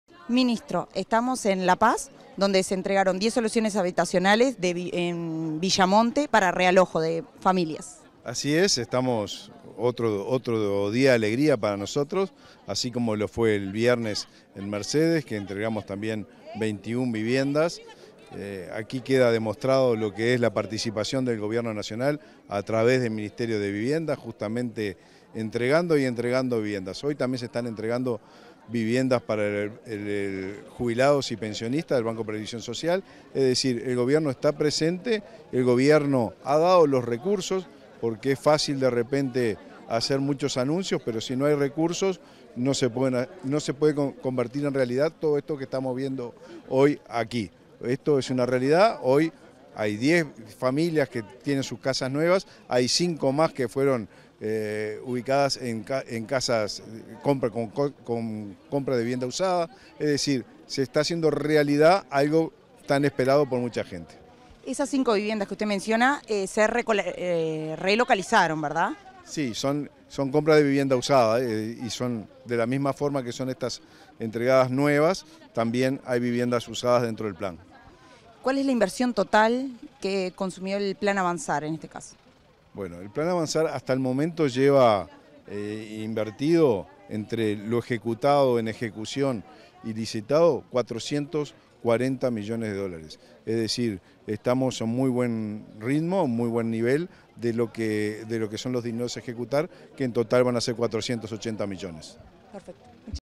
Entrevista al ministro de Vivienda y Ordenamiento Territorial, Raúl Lozano
Tras la entrega de 10 soluciones habitacionales en La Paz, Canelones, este 9 de octubre, Comunicación Presidencial dialogó con el ministro de